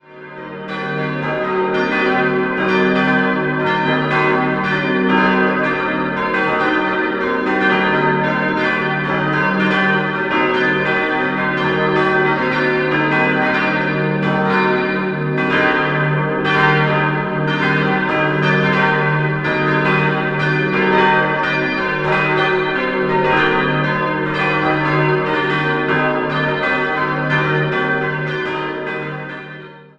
5-stimmiges Geläut: es'-g'-b'-c''-d'' Die Glocken 1, 3 und 5 sind historisch und wurden 1435 von Meister Ulricus (Nürnberg), 1730 von Christian Victor Herold (Nürnberg) und 1260 von einem unbekannten Meister gegossen.